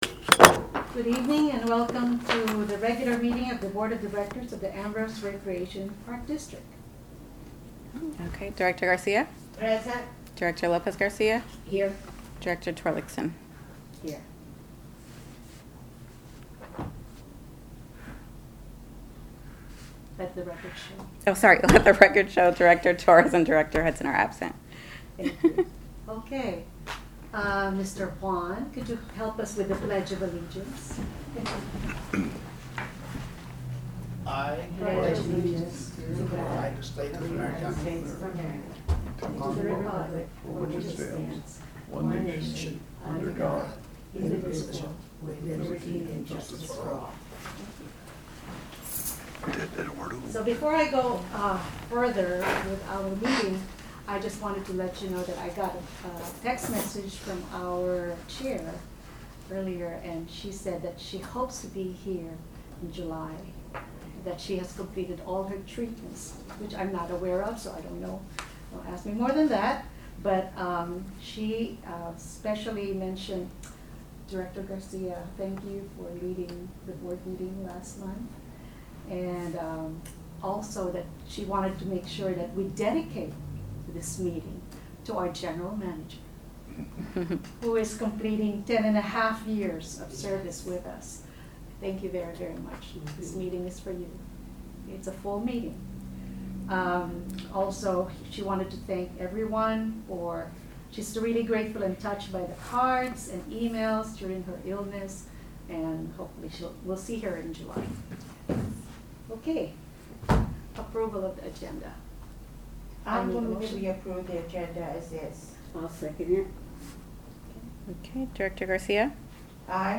Board Meeting